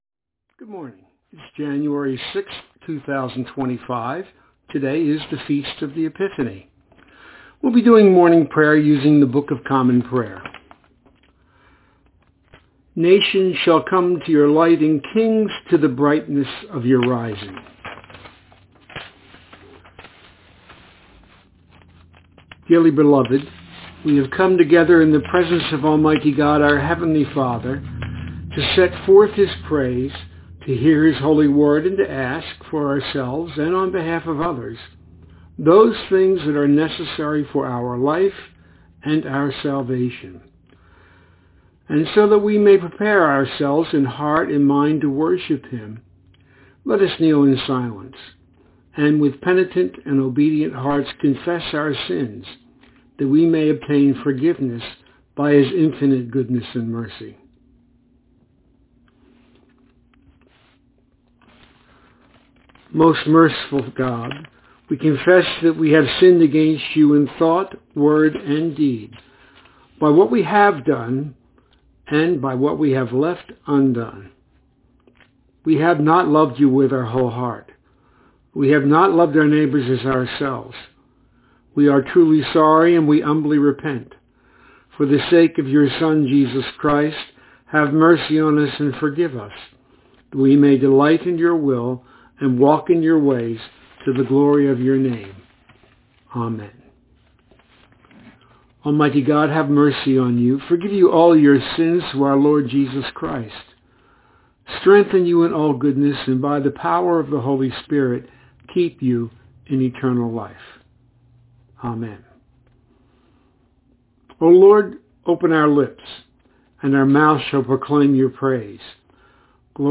Morning Prayer for Monday, 6 January 2025